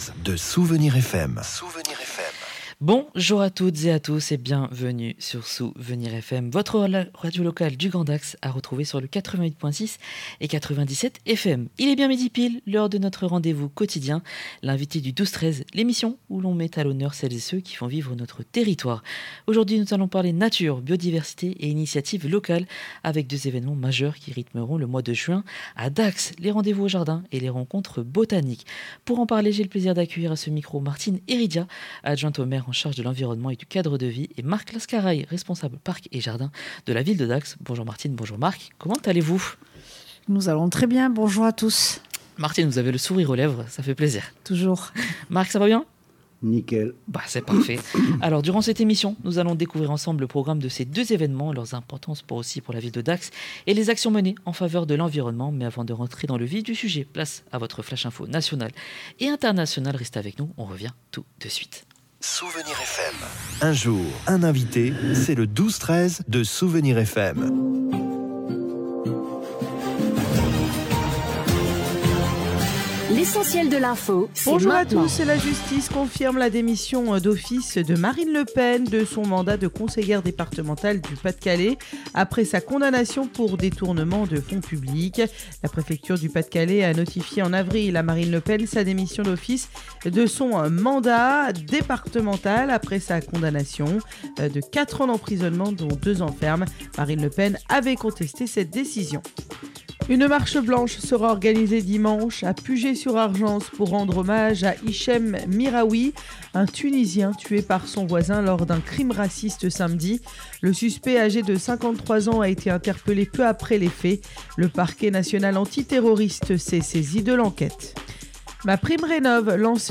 Ce midi, dans « L’invité du 12/13h » sur Souvenirs FM, nous avons reçu Martine Éridia, adjointe au Maire de Dax en charge de l’Environnement et du Cadre de vie